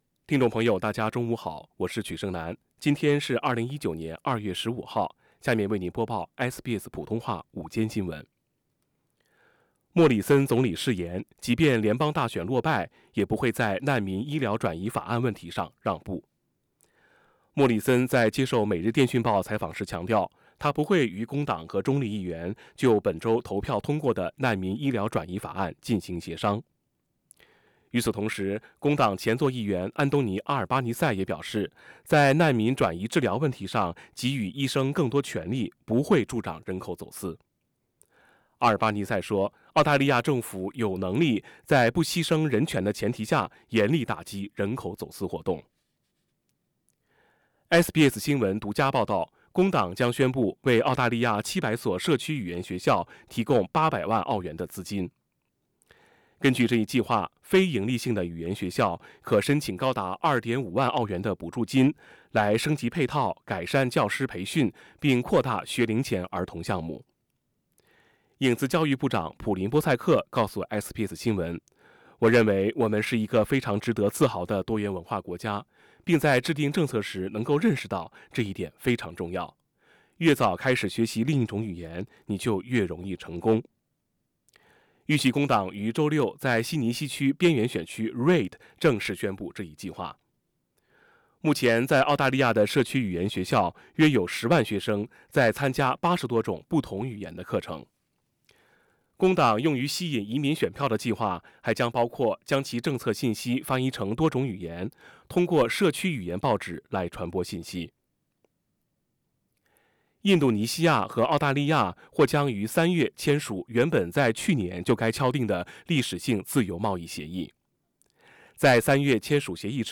SBS午間新聞 （2月15日）
SBS Midday News Source: Pixabay
midday_news_feb_15.mp3